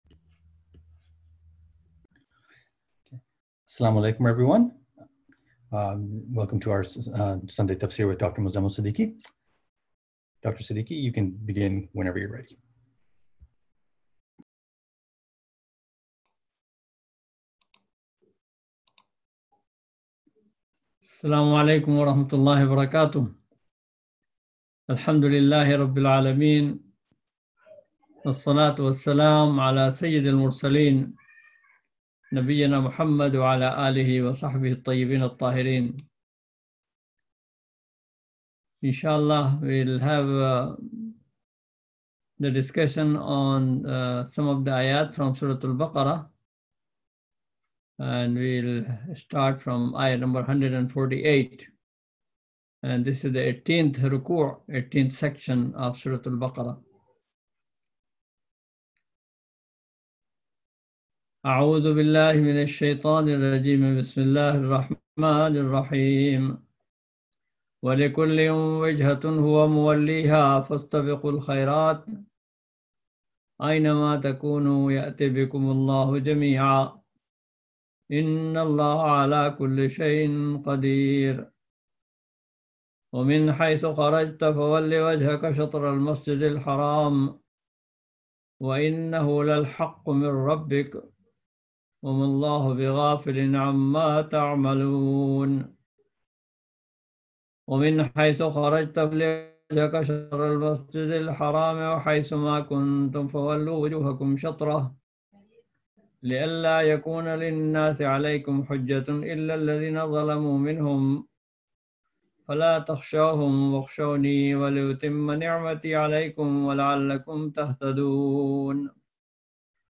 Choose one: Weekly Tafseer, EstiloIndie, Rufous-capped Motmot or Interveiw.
Weekly Tafseer